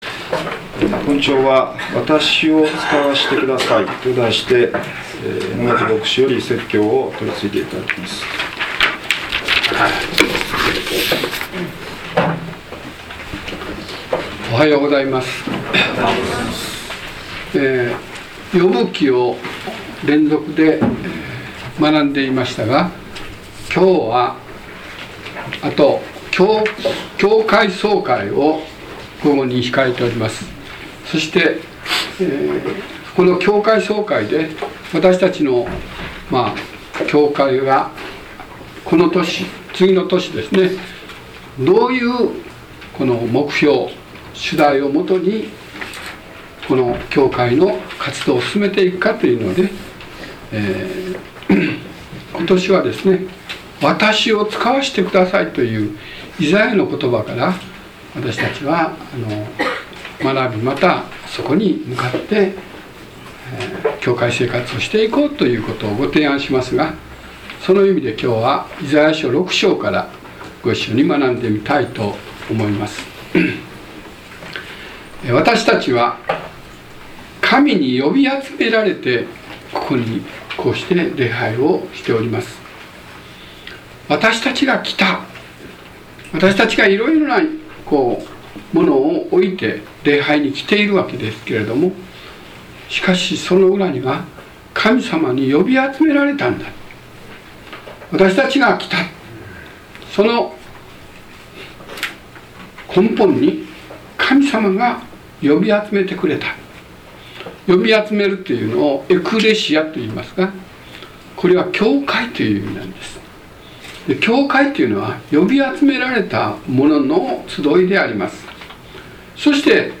説教要旨 2013年3月17日 わたしを遣わしてください | 日本基督教団 世光教会 京都市伏見区